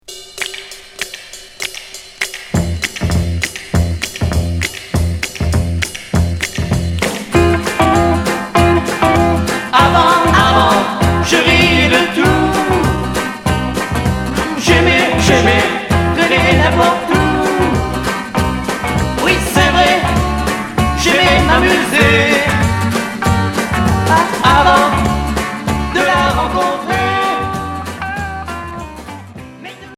Jerk
Beat